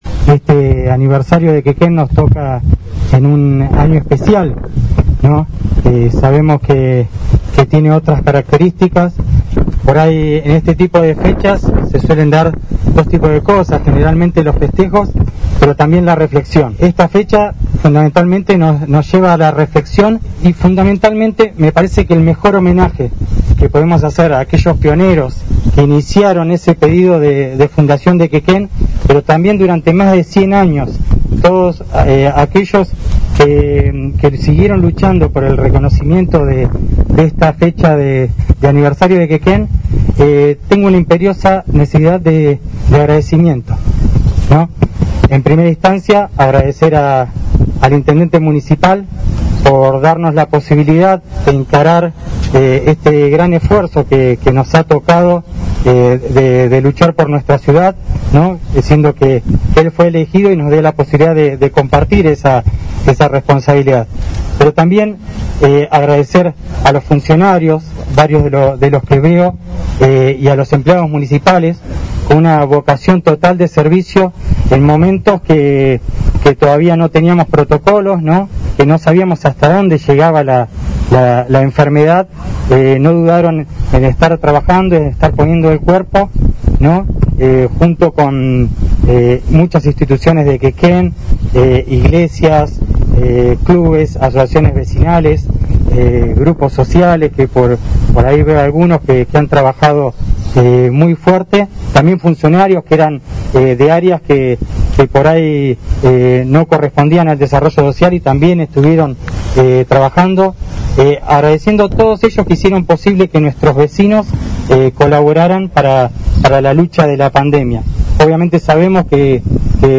Acompañado por el delegado municipal y autoridades de distintas instituciones, en una pequeña ceremonia que se desarrolló en la Plaza de los Niños el intendente Arturo Rojas encabezó esta mañana el acto oficial que sirvió para conmemorar el aniversario centésimo sexagésimo sexto de la localidad de Quequén.